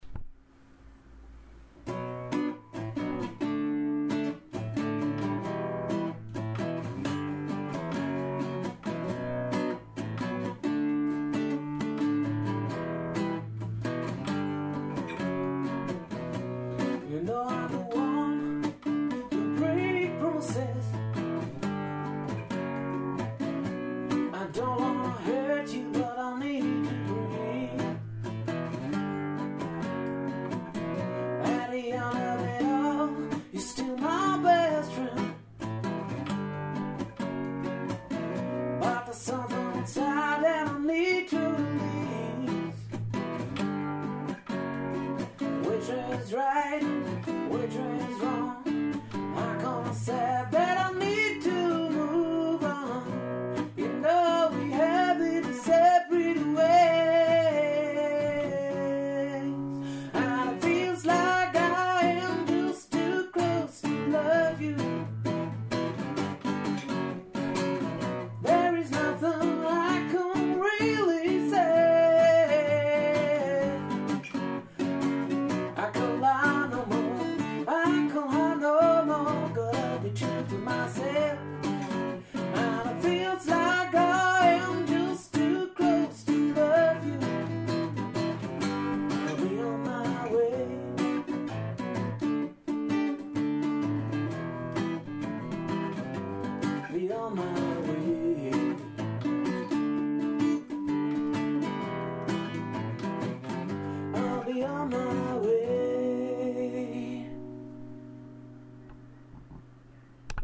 1 Stimme, eine Gitarre
schöner Sound im kleinen Rahmen
• Unplugged